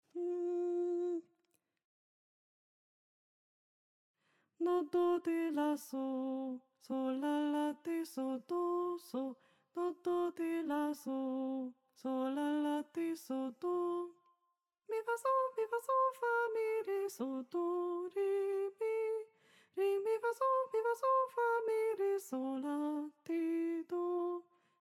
Régi barokk táncok és új stílusú magyar népdalok, táncdallamok
202_Purcell: Rigadoon (F) nyugodtabb tempóban
202b.-lass_purcell_-_rigadoon_f.mp3